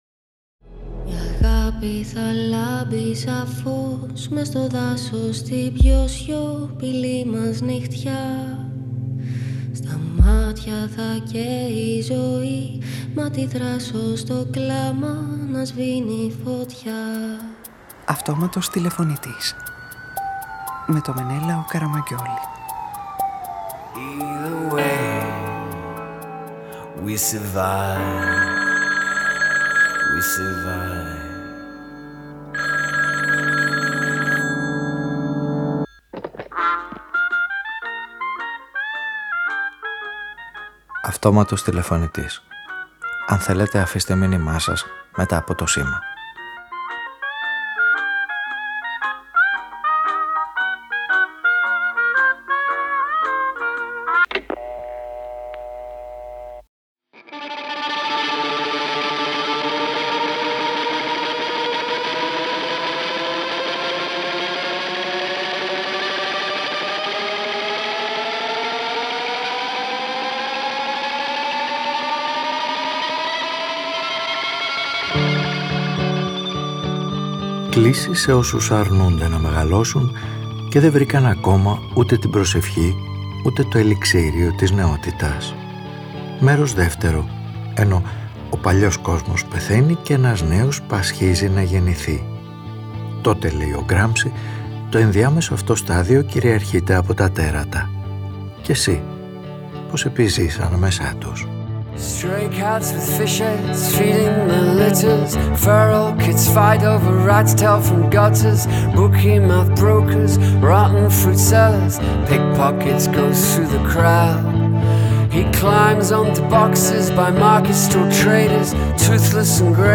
Ραδιοφωνικη Ταινια